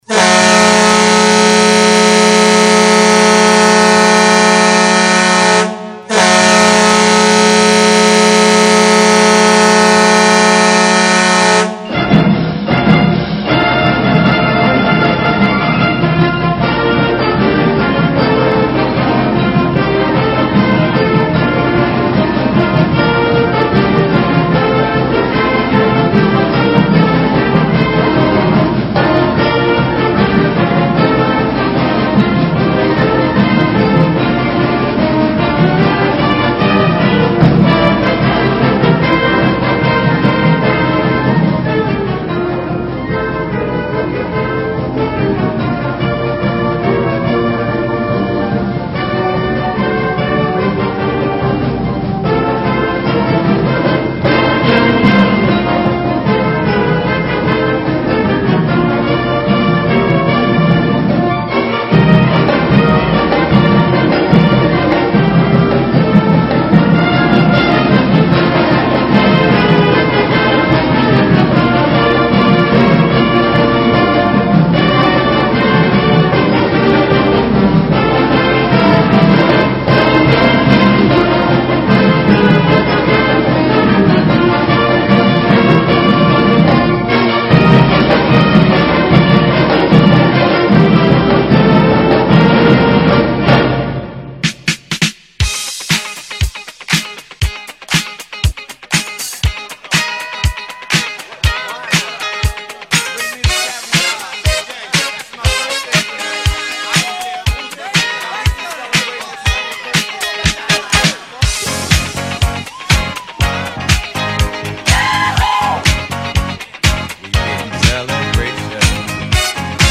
Category: Sports   Right: Personal